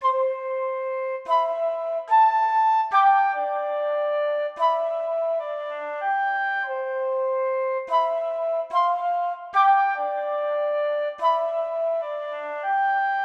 Billion Benz_Flute.wav